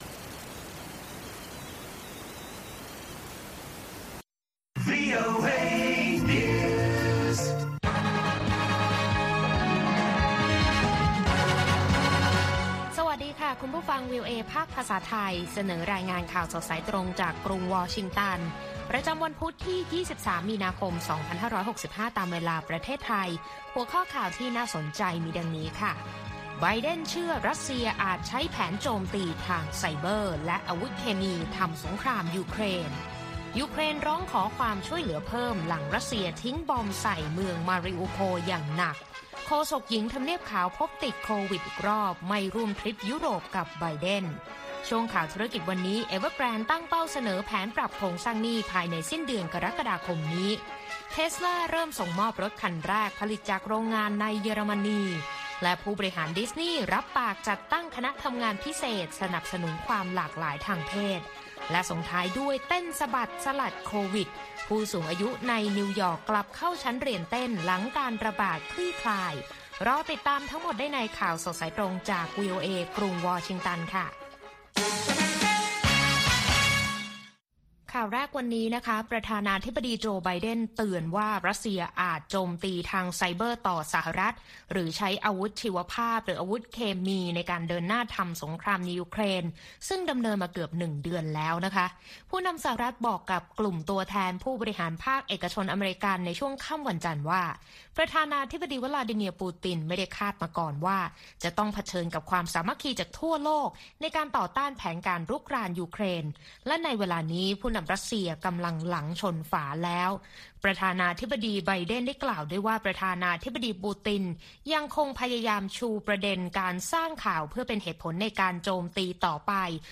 ข่าวสดสายตรงจากวีโอเอ ภาคภาษาไทย วันพุธ ที่ 23 มีนาคม 2565